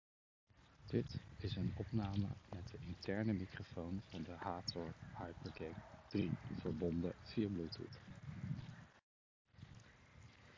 Van de HATOR Hypergang 3 Wireless zijn twee opnames, met de detachable microfoon en met de ingebouwde microfoon.
In de opname van de interne microfoon is goed te horen dat het volume een stuk lager ligt.
HATOR-Hypergang-3-interne-microfoon.mp3